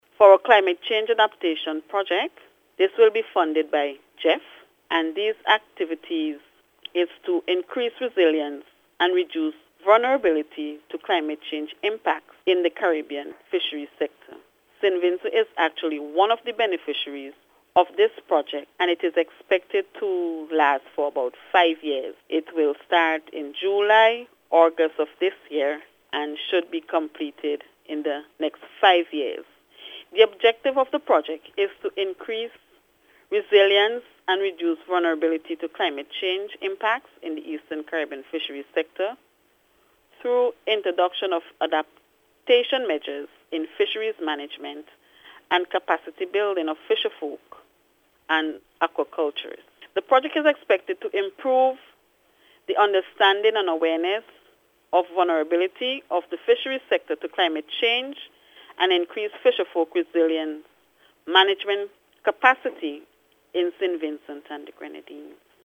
This was revealed by Chief Fisheries Officer, Jennifer Cruickshank Howard during an interview with NBC News.